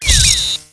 misc.error.wav